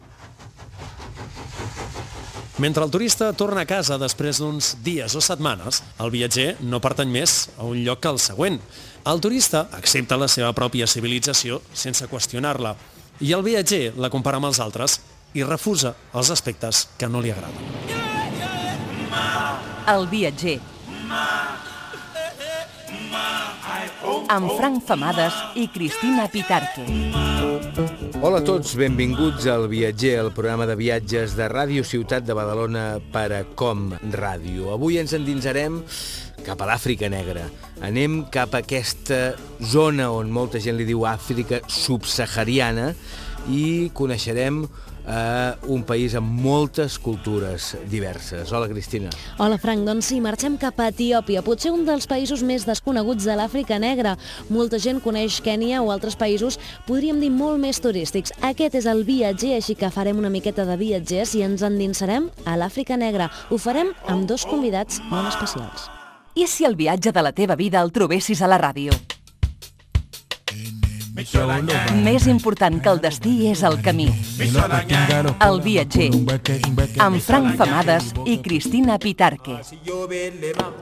Inici del programa: presentació, tema i indicatiu
Divulgació
FM